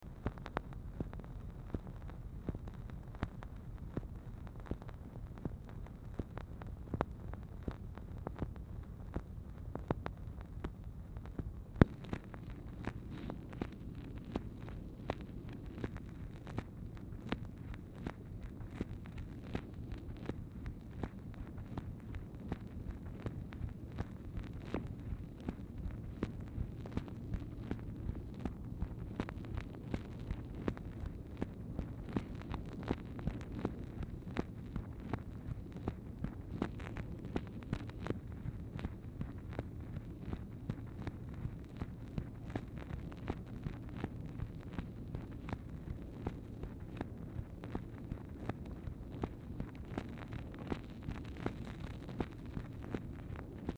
Telephone conversation # 2712, sound recording, MACHINE NOISE, 3/30/1964, time unknown | Discover LBJ
Telephone conversation
Format Dictation belt